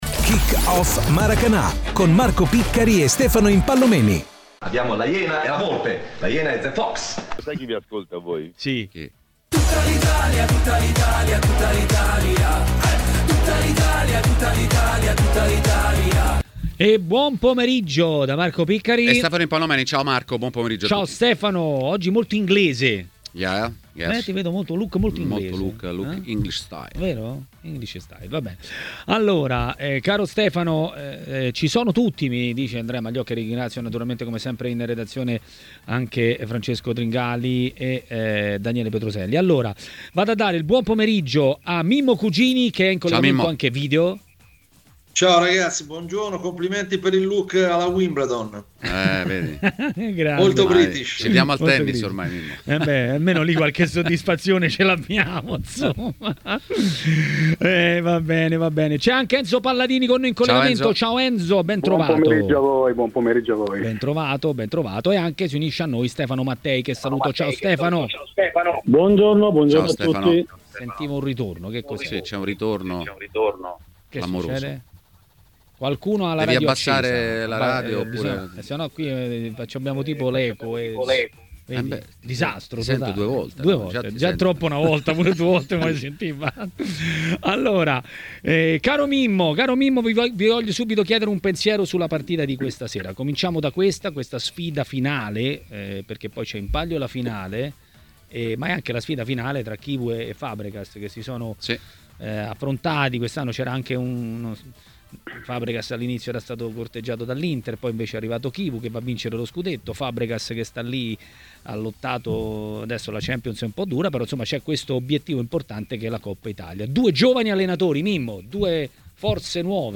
Le Interviste
ai microfoni di Tmw Radio , durante 'Maracanà'.